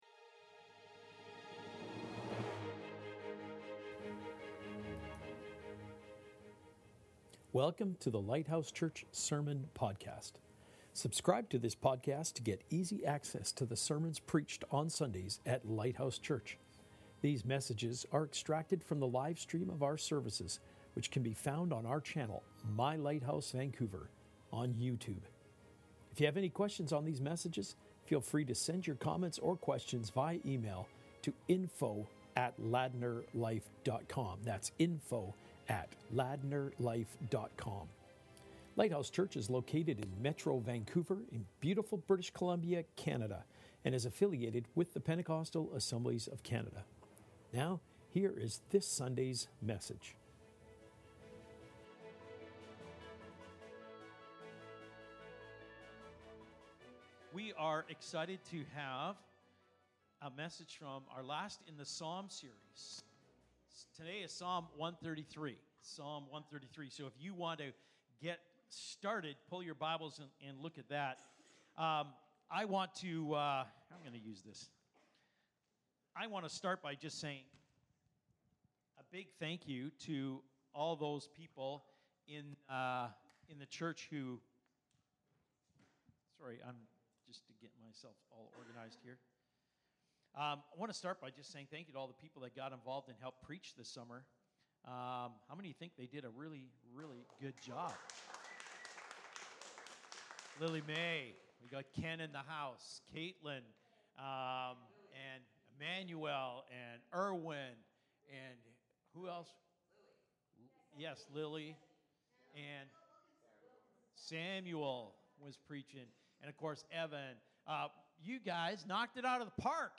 Sermons | Lighthouse Church